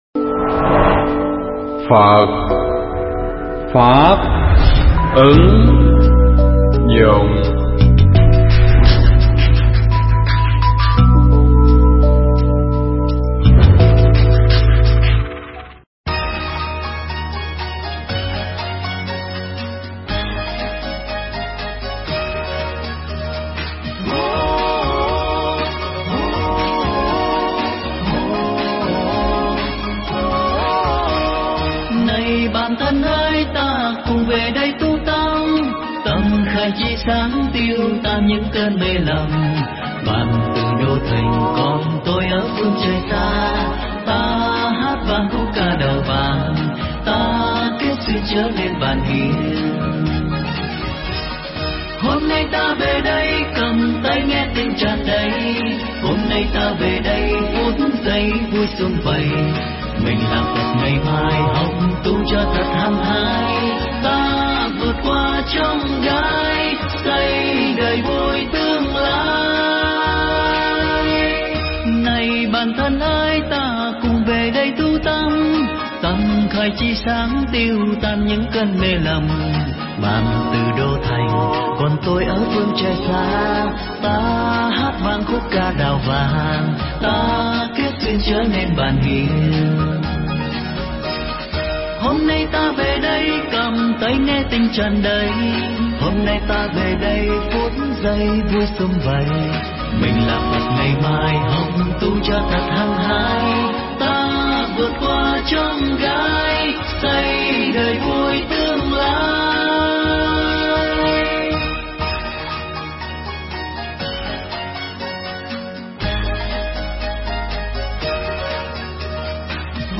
Nghe Mp3 thuyết pháp Chuyển Hóa Tâm Bệnh
Mp3 pháp thoại Chuyển Hóa Tâm Bệnh